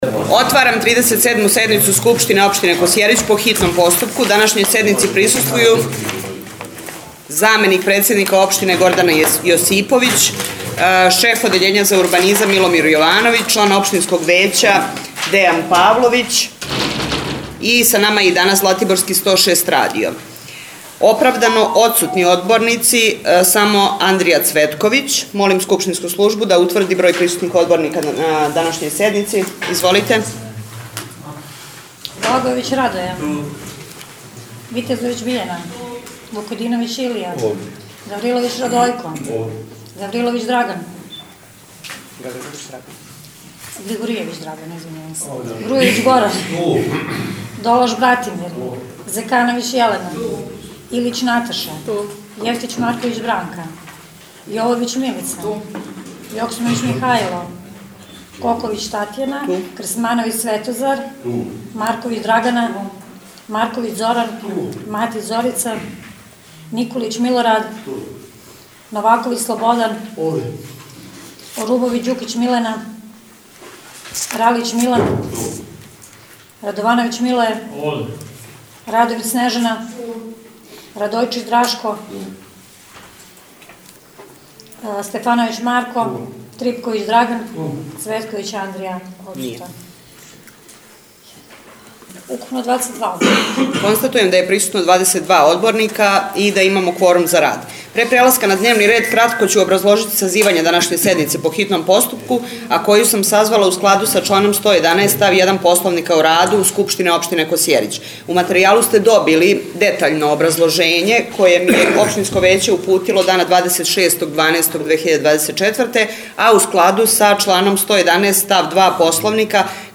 37. СЕДНИЦУ СКУПШТИНЕ ОПШТИНЕ КОСЈЕРИЋ ПО ХИТНОМ ПОСТУПКУ